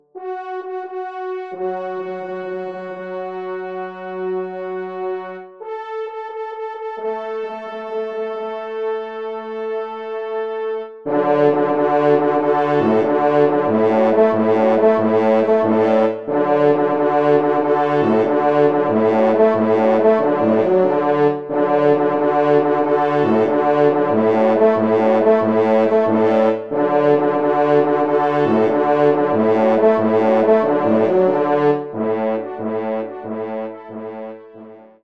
Genre : Divertissement pour Trompes ou Cors
Pupitre 4° Cor